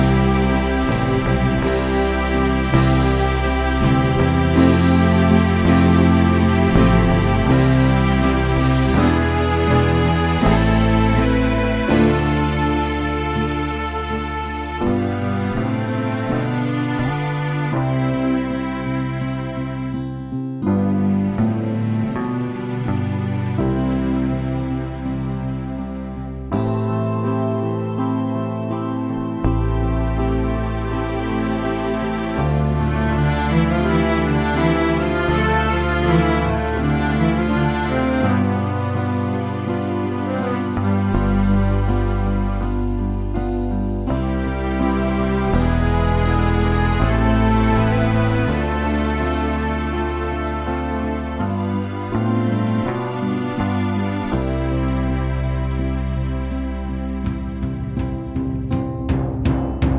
Minus Main Guitars Indie / Alternative 2:45 Buy £1.50